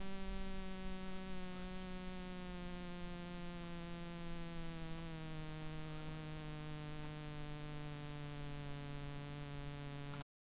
Rode NT1-A Geräusche (Phantomspeisung?)
Der Sound durchläuft gefühlt das komplette Frequenzspektrum, auf der Aufnahme brummt es recht tief. Es driftet mit der Zeit aber auch hin zu höheren Frequenzen. Hört sich für mich wie ein Ladevorgang der Kondensatoren an, kann es aber nicht mit Sicherheit sagen.